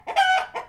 singleChickenDie.mp3